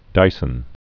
(dīsən), Freeman John Born 1923.